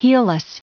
Prononciation du mot heelless en anglais (fichier audio)
Prononciation du mot : heelless